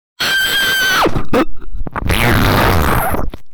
Scream Burp Fart Sound Effect Download: Instant Soundboard Button
Scream Burp Fart Sound Button - Free Download & Play